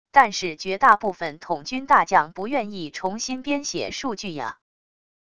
但是绝大部分统军大将不愿意重新编写数据啊wav音频生成系统WAV Audio Player